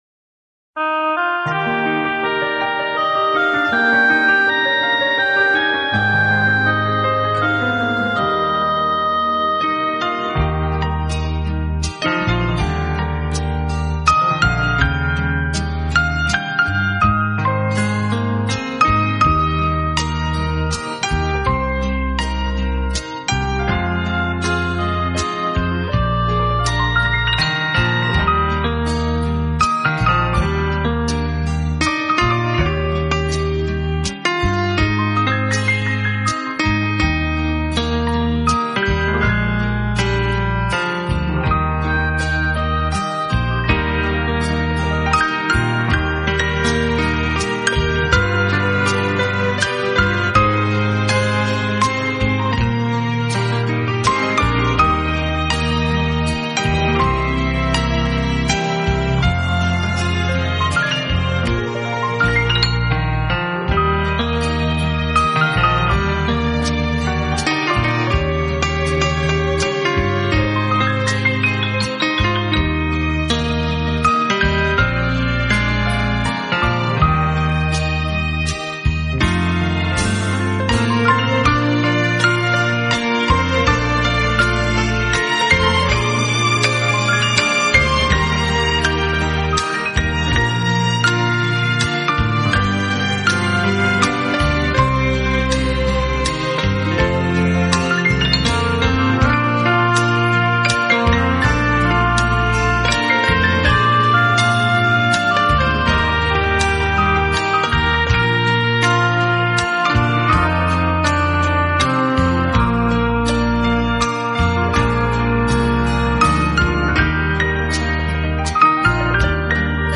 钢琴演奏版，更能烘托出复古情怀，欧美钢琴大师深具质感的演奏功力，弹指
本套CD全部钢琴演奏，